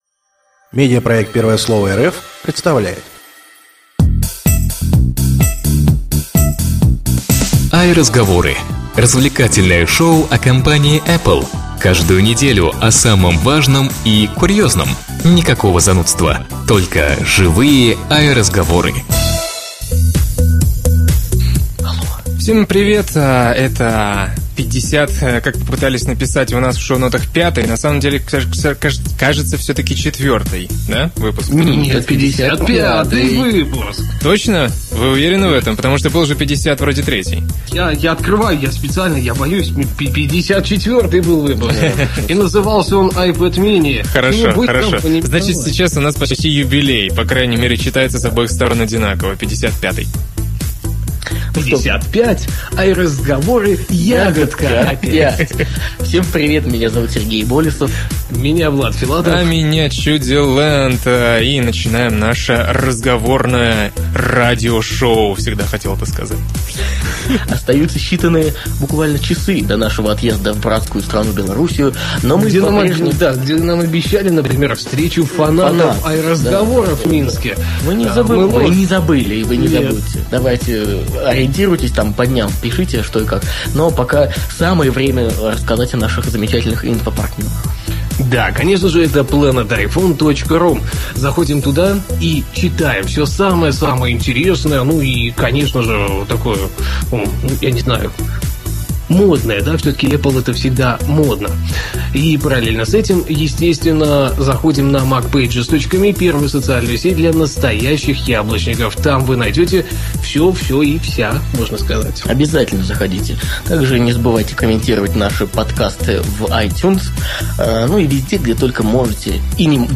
stereo Ведущие аудиошоу в свободной и непринужденной манере расскажут вам обо всех самых заметных событиях вокруг компании Apple за прошедшую неделю. Никакой начитки новостей, занудства, только живые "АйРазговоры".